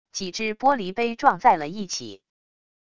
几只玻璃杯撞在了一起wav音频